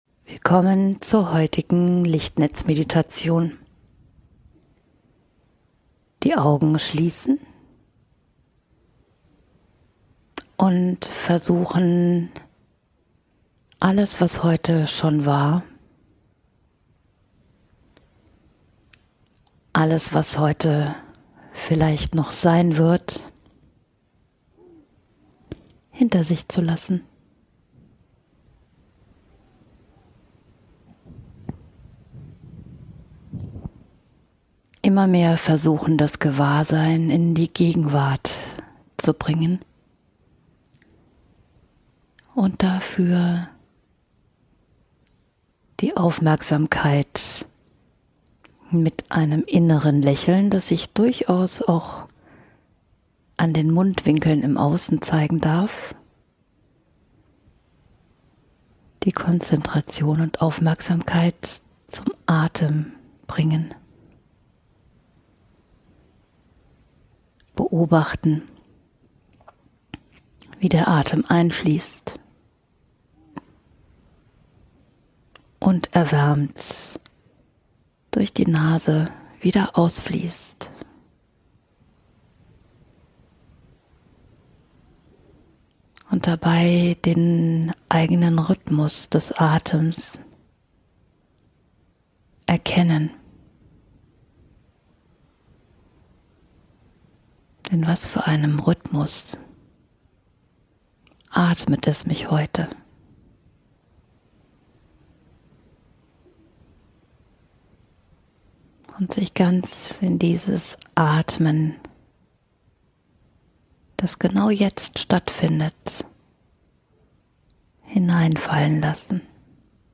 In dieser Winter-Meditation begegnest du deinem inneren Kind. Gemeinsam taucht ihr in eine weihnachtliche Zauberwelt voller Geborgenheit, Sicherheit und Wohlgefühl ein, in der du ihm ein unerwartet schönes Geschenk machst.